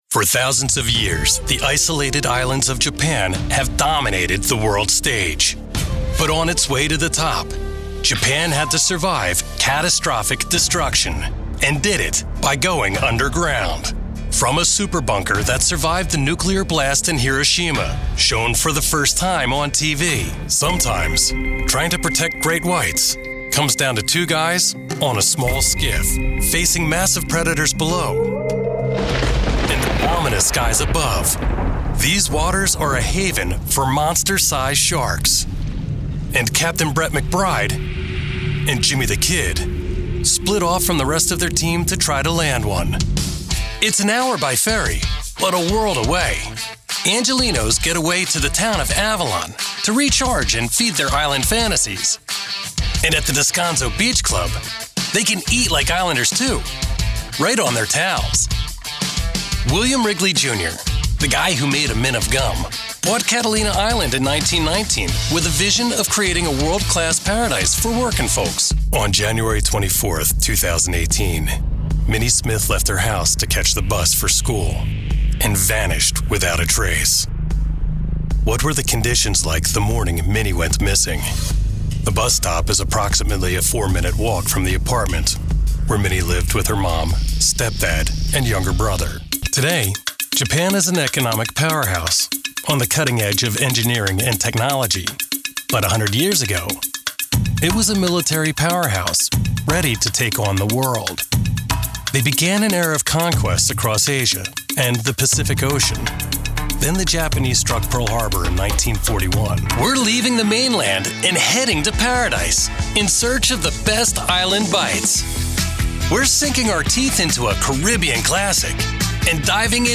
Male
Adult (30-50)
Friendly, middle aged, father, confident, construction worker, professional, conversational, warm, authentic, engaging, deep, authentic, intimate, articulate, guy next door, informed, knowledgeable
Tv Documentary Demo
Words that describe my voice are confident, middle aged, warm.